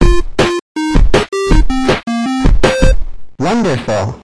victory_ivy.ogg